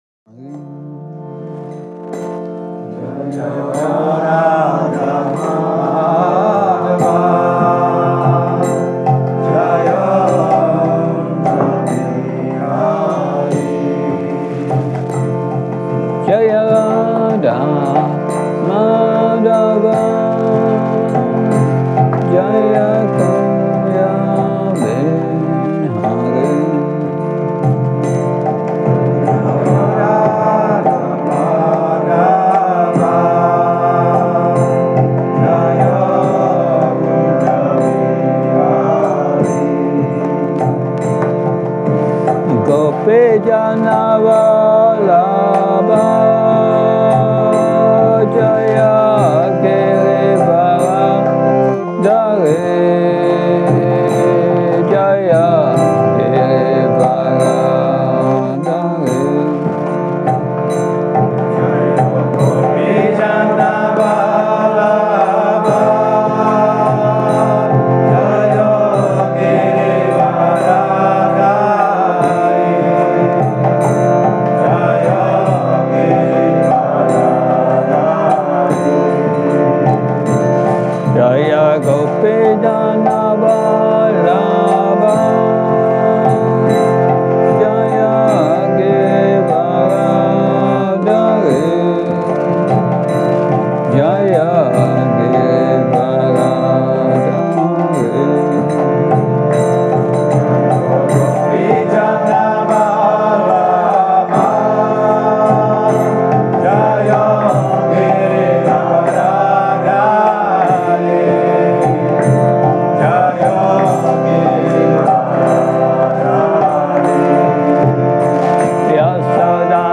Přednáška SB-4.28.43 Krišnův dvůr